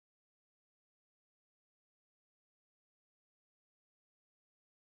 5秒しかありませんが、結合またはトリミング編集を行ってサンプルを長くまたは短くすることができます。
◆無音サンプル.mp3
silence-audio.mp3